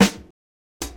• Large Room Steel Snare Drum Sample G Key 170.wav
Royality free snare tuned to the G note. Loudest frequency: 1643Hz
large-room-steel-snare-drum-sample-g-key-170-ncp.wav